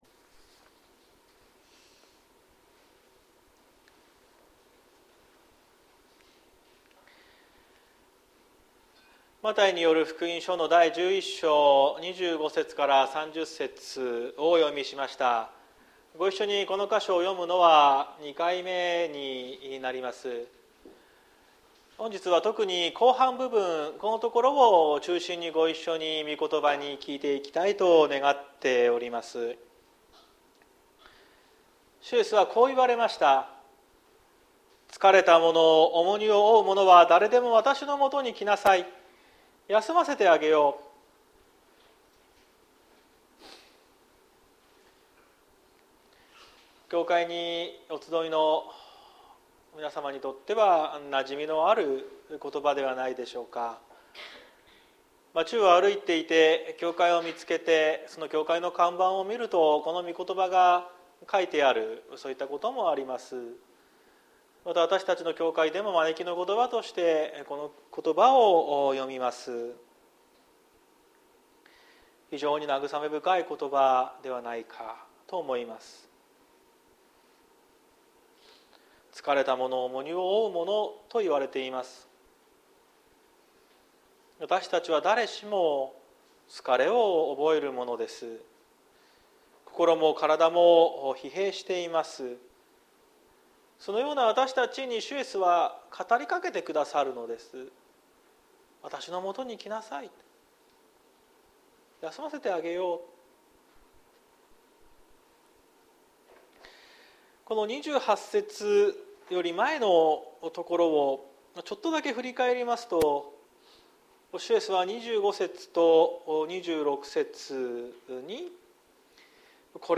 2024年02月04日朝の礼拝「キリストにある安らぎ」綱島教会
綱島教会。説教アーカイブ。